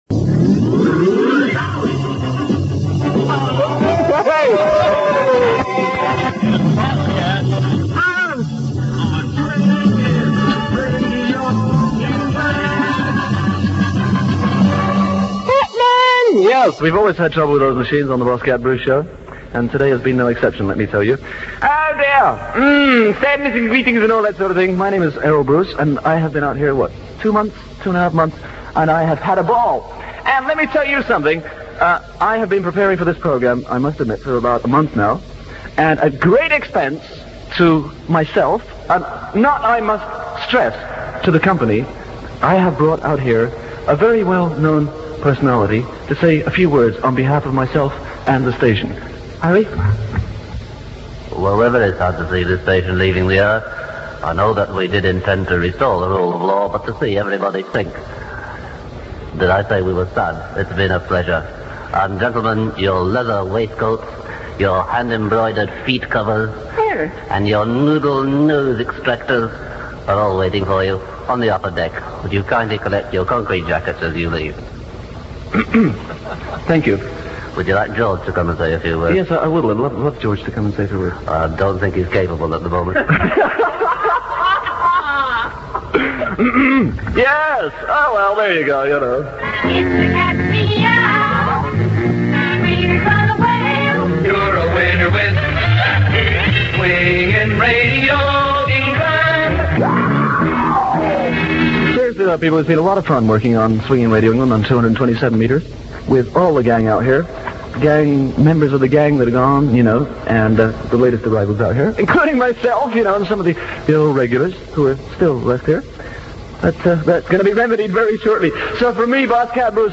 does his impression of Prime Minister Harold Wilson one last time